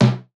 SOUTHSIDE_percussion_og_tom.wav